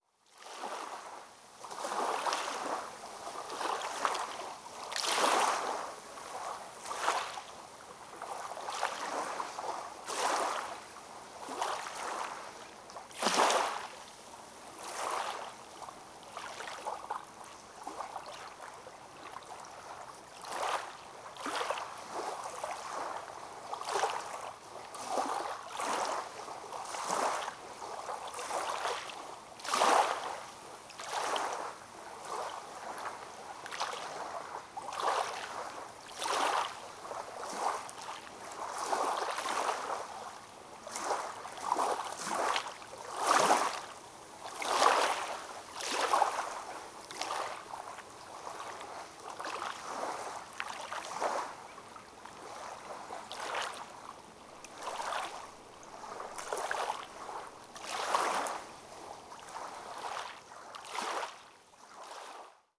the sound of gentle waves at elliots beach park on oyster bay
We came across this little beach filled with crushed seashells and a few small shorebirds during an exploratory drive.
I was too preoccupied recording and taking photographs to really pay attention to the birds, but you can hear their soft calls faintly in the recording.